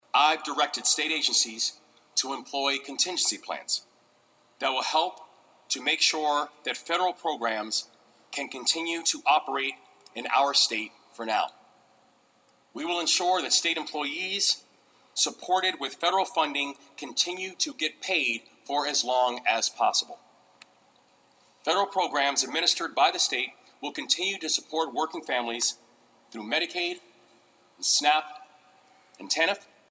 Maryland Governor Wes Moore at a news conference this morning discussed his action plan being implemented now in the wake of the federal government shutdown.